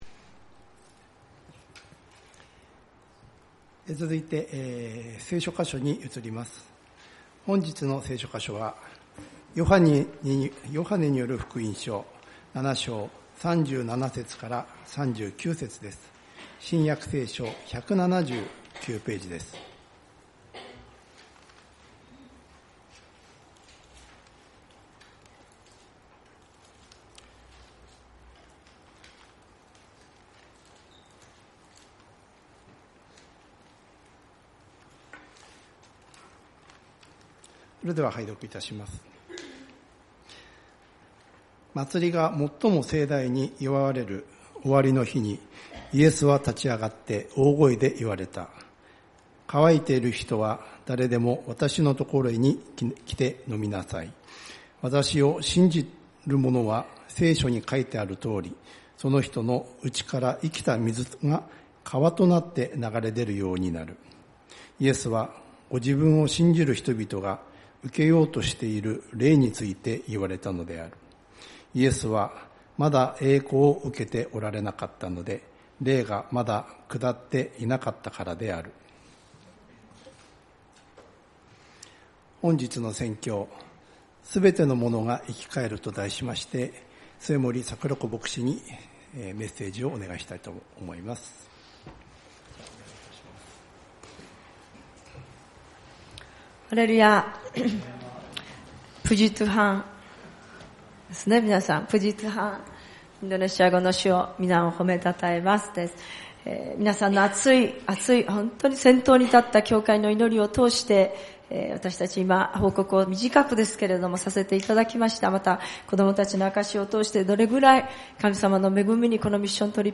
子ども若者祝福礼拝「すべてのものが生き返る」ヨハネによる福音書 7:37-39